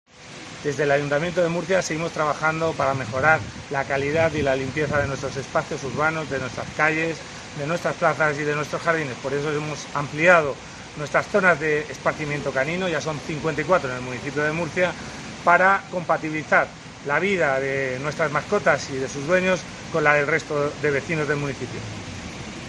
José Guillén, concejal de Desarrollo Urbano